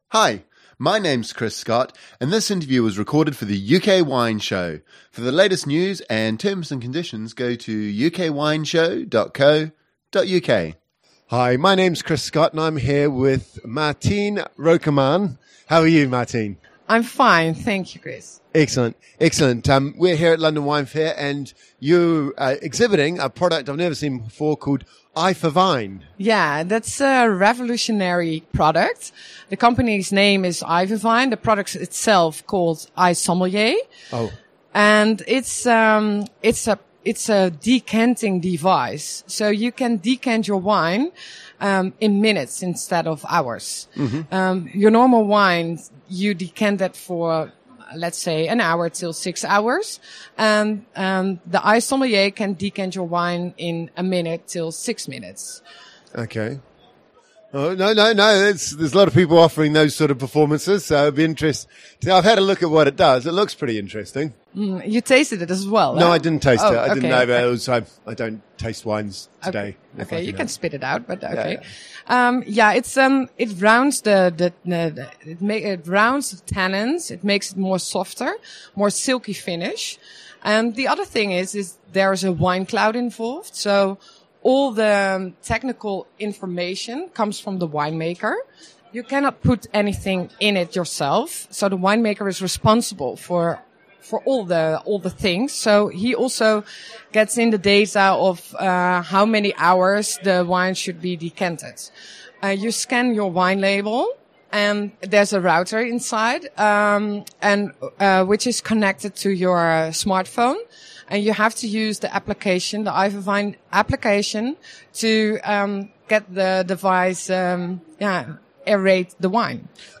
The music used for the UK Wine Show is Griffes de Jingle 1 by Marcel de la Jartèle and Silence by Etoile Noire.